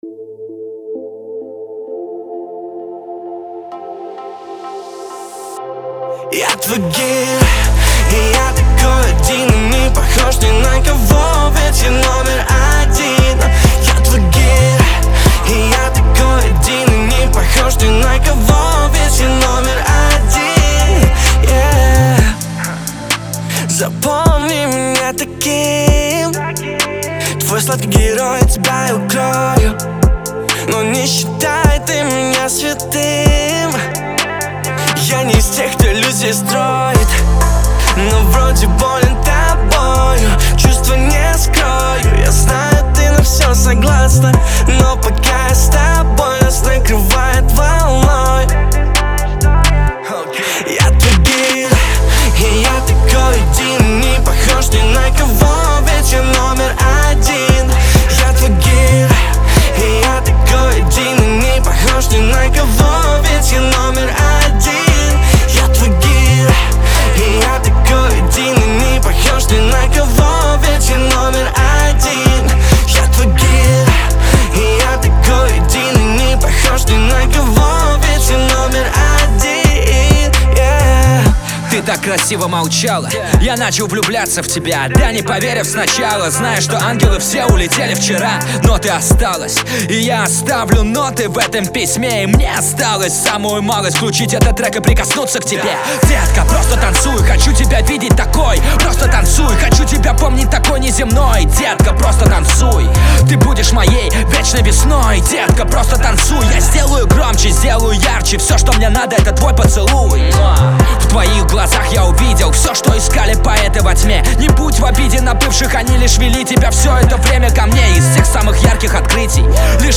это яркая и эмоциональная композиция в жанре поп